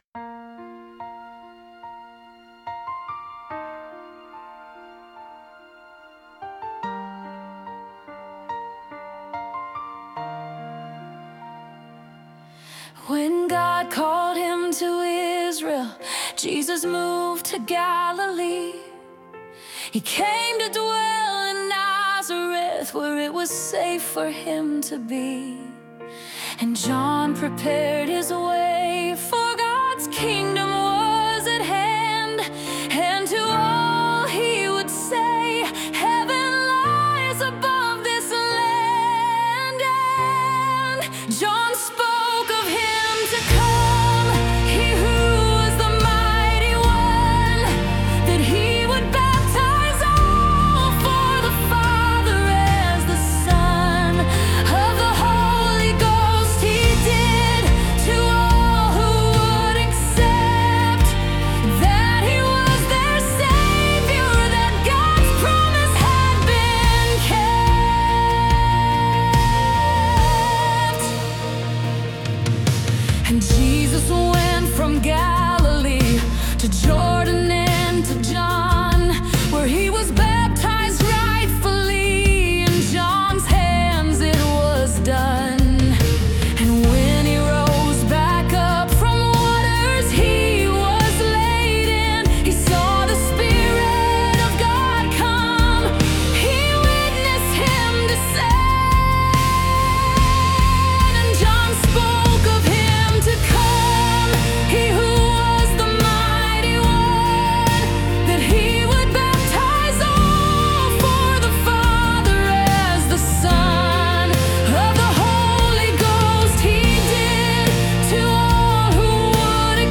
Complete Christian Song